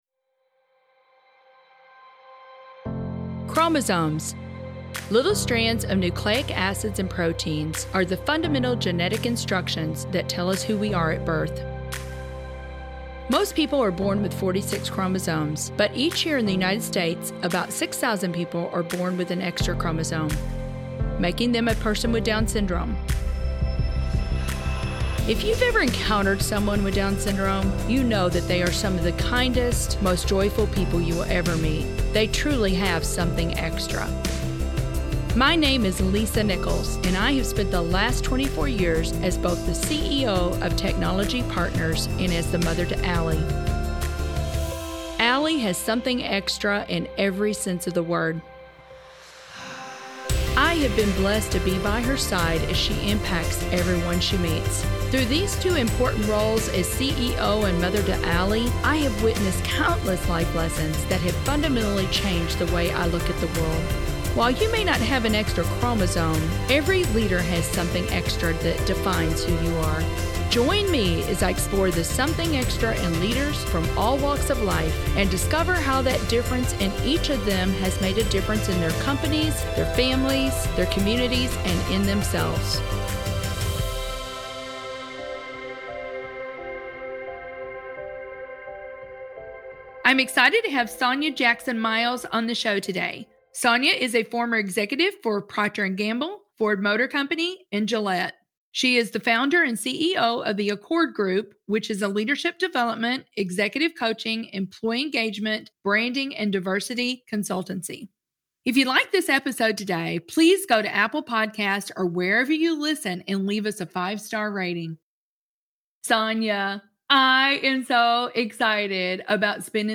Credits: Lisa Nichols, Host